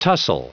Prononciation du mot tussle en anglais (fichier audio)
Prononciation du mot : tussle